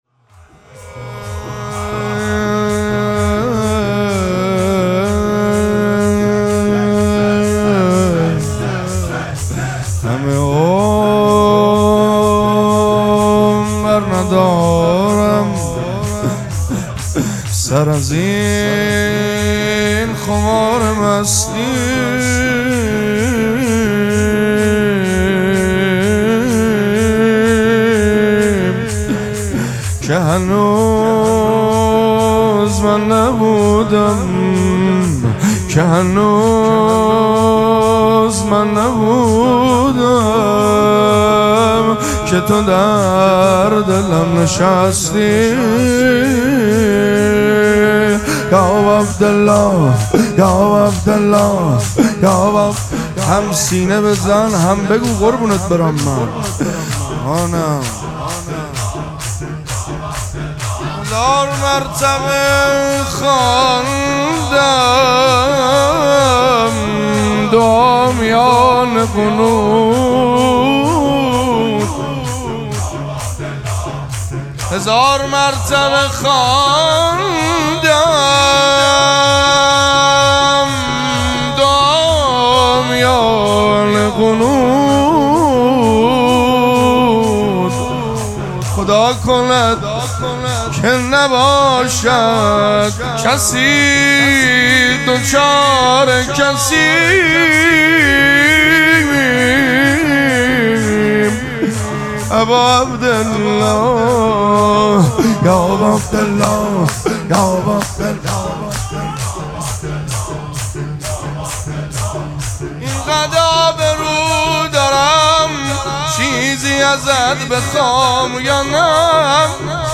مراسم مناجات شب دهم ماه مبارک رمضان
حسینیه ریحانه الحسین سلام الله علیها
شور